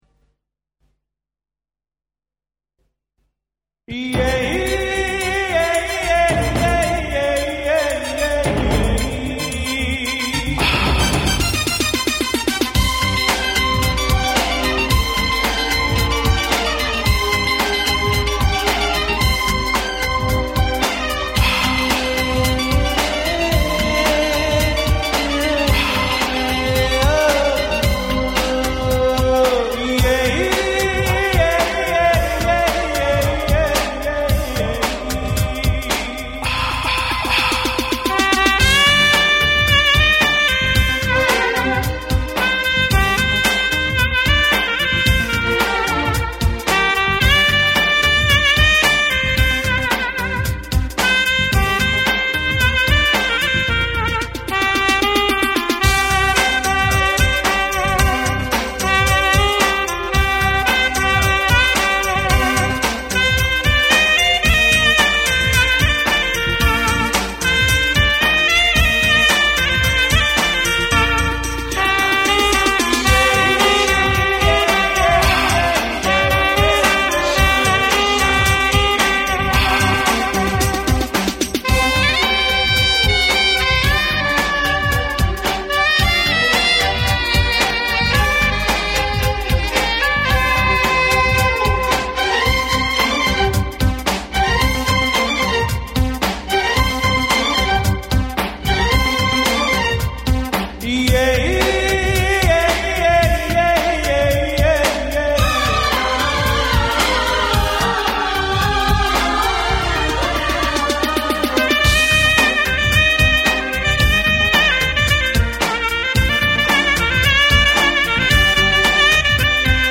Instrumental Songs